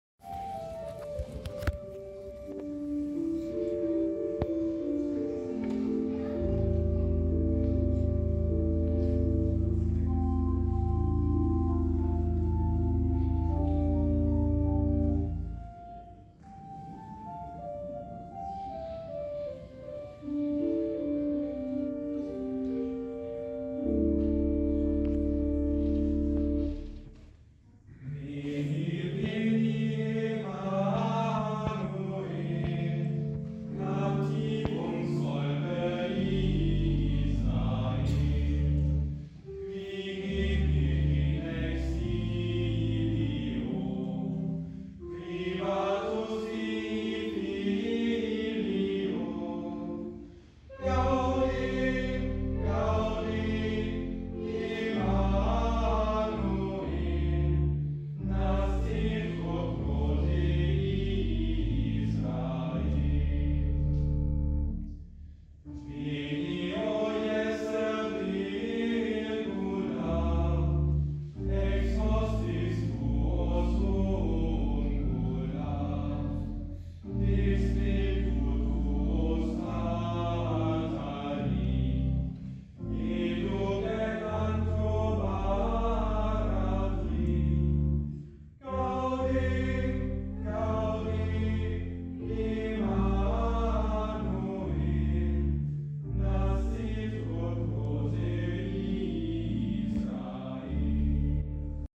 Veni, Veni Emmanuel - gesungen von der Jungenschola aus Ratingen im Kloster Angermund am 1. Advent.